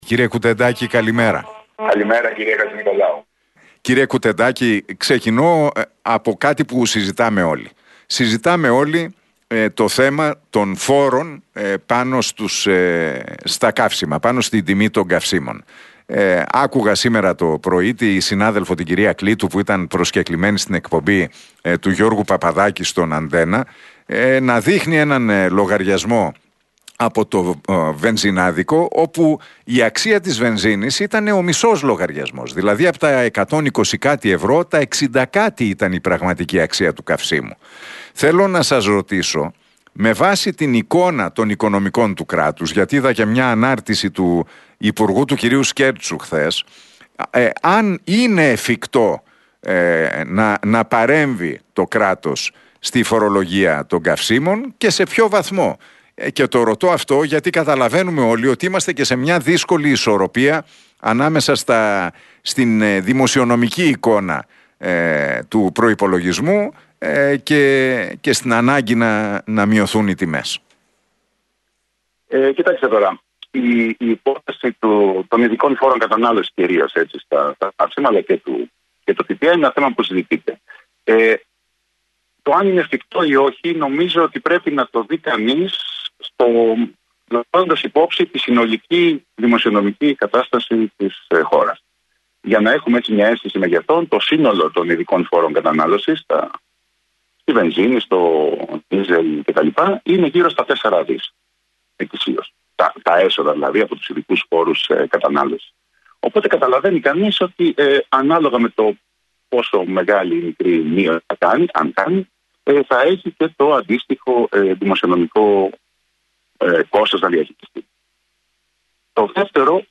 Ο Φραγκίσκος Κουτεντάκης, επικεφαλής του Γραφείου Προϋπολογισμού του Κράτους στη Βουλή, παραχώρησε συνέντευξη στην εκπομπή του Νίκου Χατζηνικολάου στον Realfm 97,8.